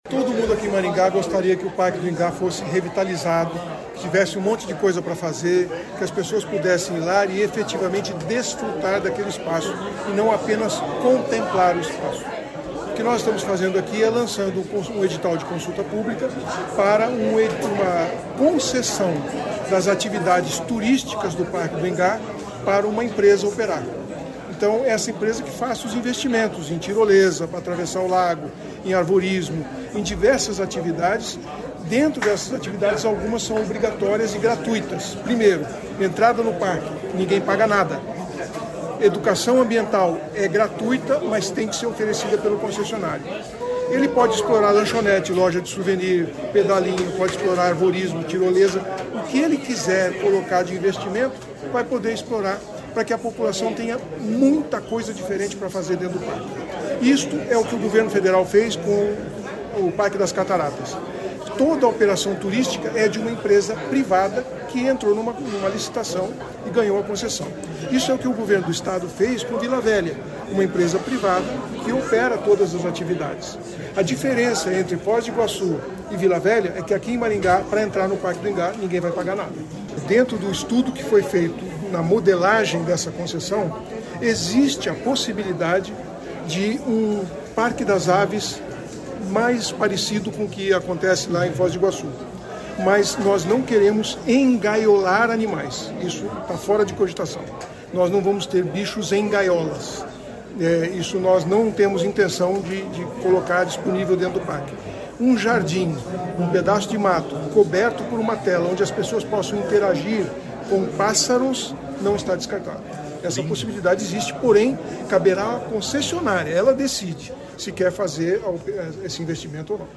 Durante cerimônia realizada nesta terça-feira (13), a Prefeitura de Maringá lançou a consulta pública do edital de concessão do Parque do Ingá.
No modelo, não se descarta a possibilidade da instalação de um parque das aves no local, disse o prefeito. Silvio Barros também falou sobre a pista emborrachada no entorno do parque, que pode ser removida.